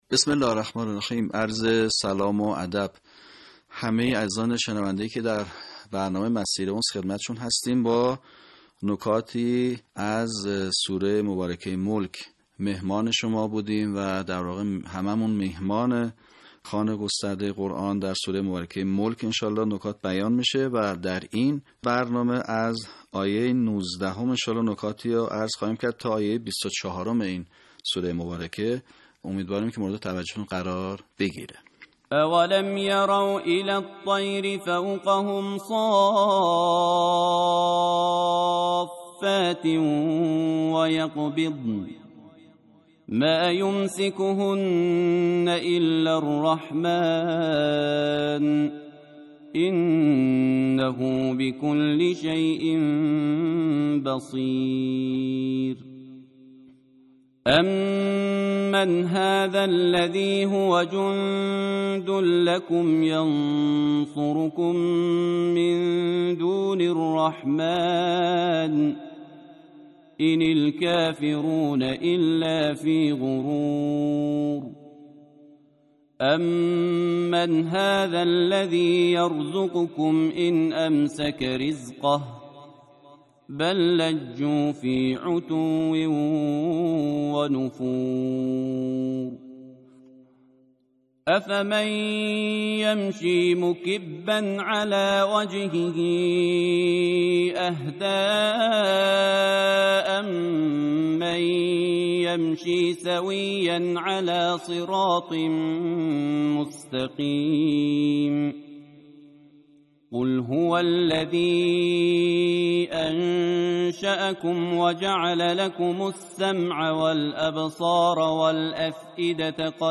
صوت | آموزش صحیح‌خوانی آیات ۱۹ تا ۲۴ سوره ملک
به همین منظور مجموعه آموزشی شنیداری (صوتی) قرآنی را گردآوری و برای علاقه‌مندان بازنشر می‌کند.